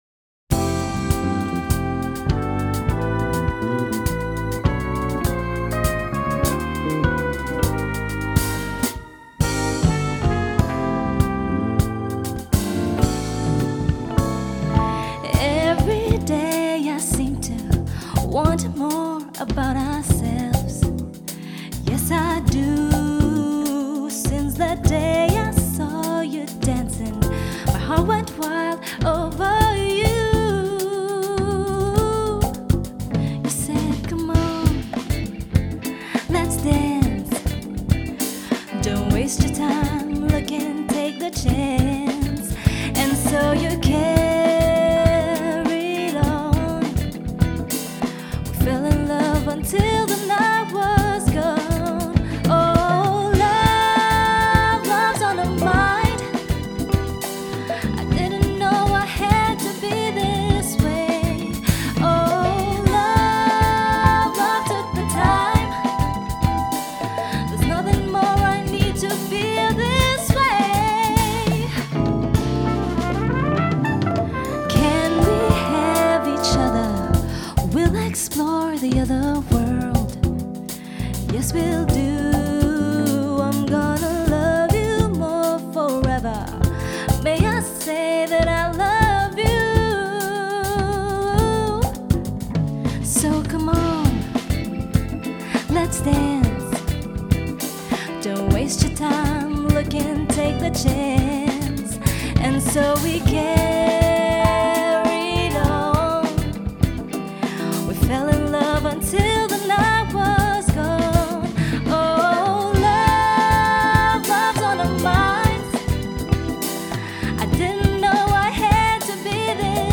メロウでトロピカルなカバー
アーバンな雰囲気を漂わせるイントロに、「TRIPPING OUT」系なリズム、サビで高揚する、これ以上ない仕上がり！
ジャンル(スタイル) JAPANESE POP / CITY POP